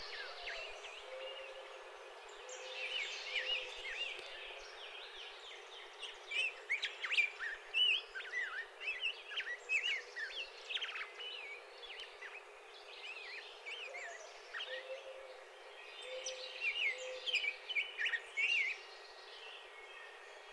Rose-breasted Grosbeak (Pheucticus ludovicianus)
The song is a subdued mellow warbling, resembling a more refined version of the American Robin’s (Turdus migratorius).
The call is a sharp pink or pick.
Rose-breasted Grosbeak from xeno-canto